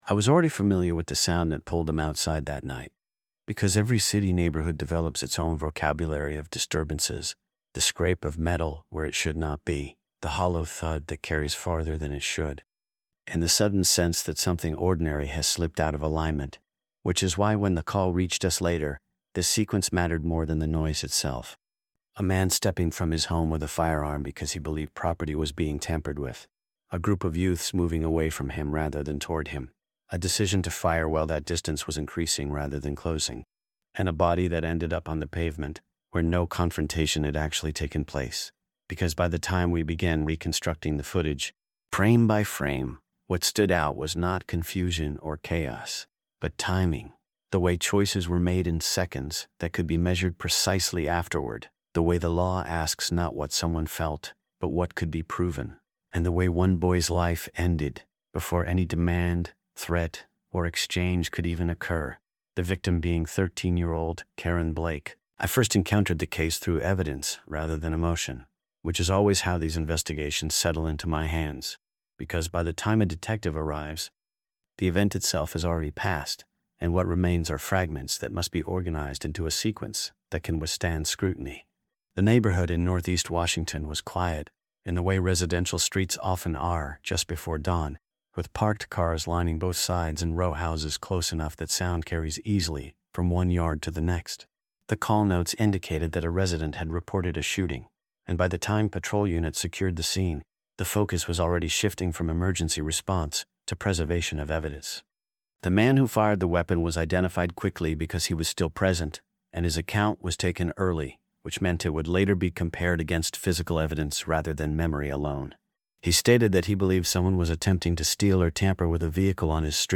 Told in a first-person detective voice and designed specifically for continuous TTS narration, the story relies on surveillance footage, forensic findings, and courtroom testimony to establish a precise timeline of events. The narrative follows the investigation from the initial 911 response through evidence collection, arrest decisions, and trial proceedings, without speculation or dramatization.